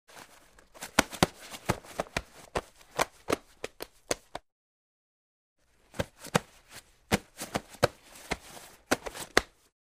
На этой странице собраны разнообразные звуки одежды: от шуршания нейлона до щелчков ремней.
Копошение в складках одежды